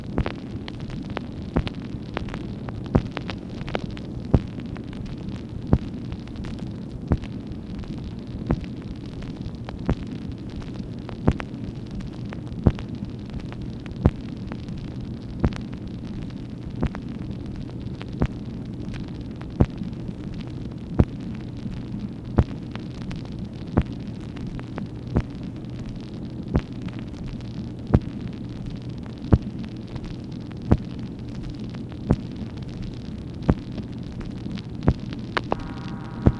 MACHINE NOISE, May 14, 1965
Secret White House Tapes | Lyndon B. Johnson Presidency